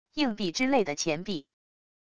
硬币之类的钱币wav音频